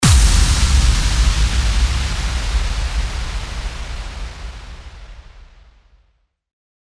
FX-1073-IMPACT
FX-1073-IMPACT.mp3